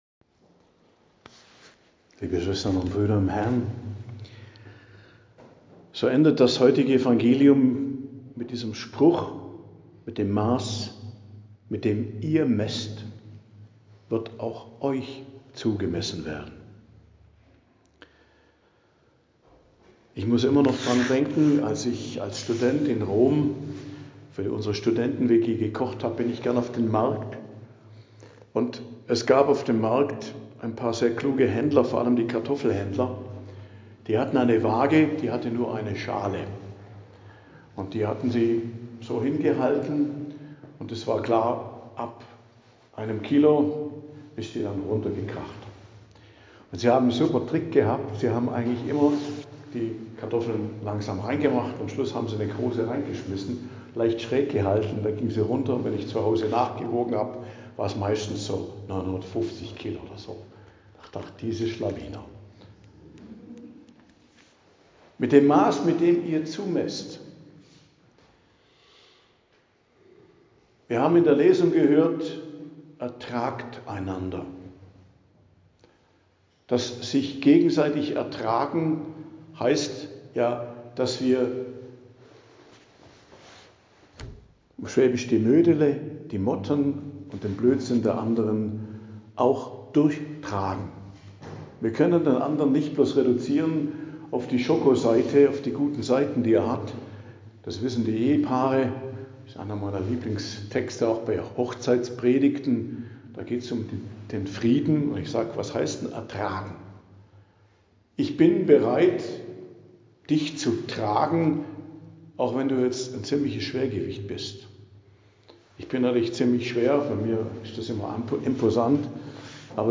Predigt am Donnerstag der 23. Woche i.J., 11.09.2025